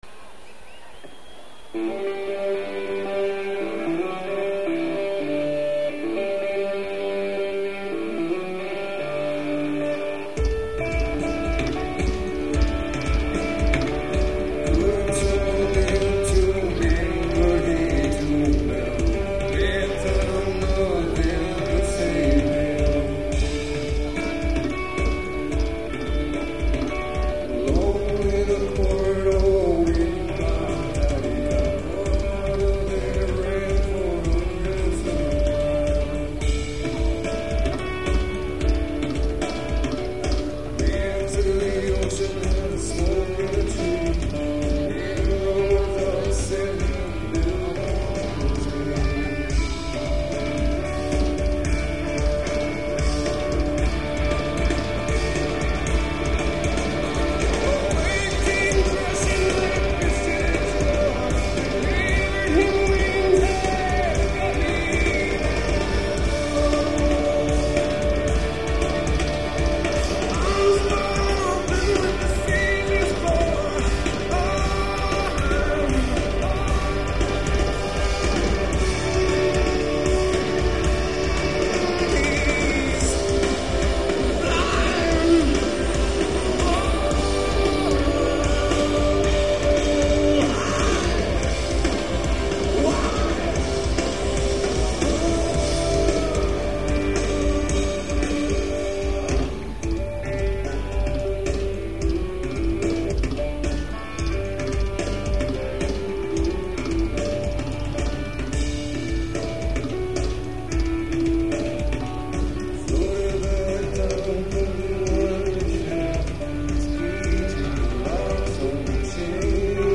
live in Oakland